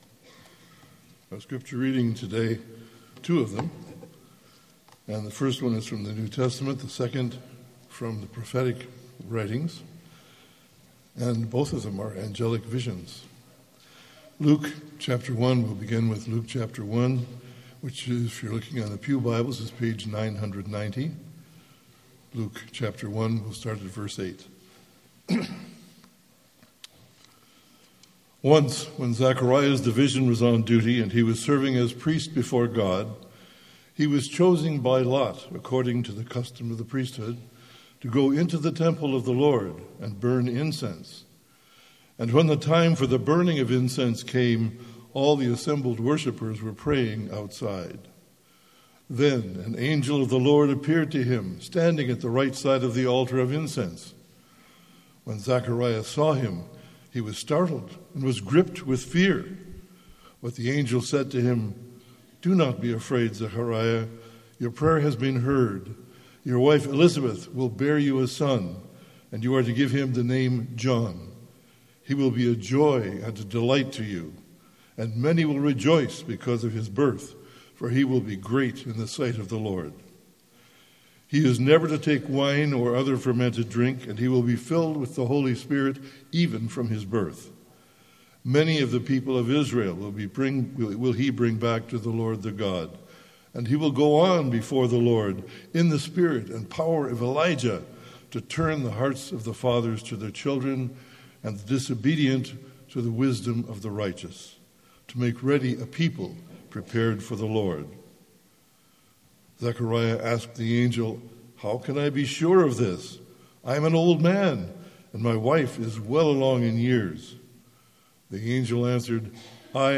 MP3 File Size: 24.9 MB Listen to Sermon: Download/Play Sermon MP3